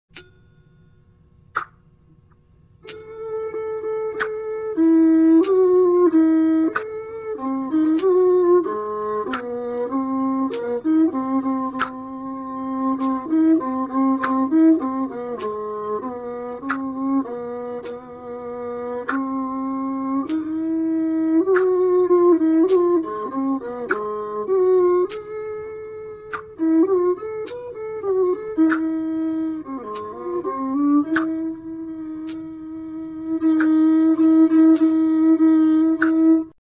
Khryang Sii : bowed instruments
The saw u is a two-stringed instrument.
This instrument has a low tone.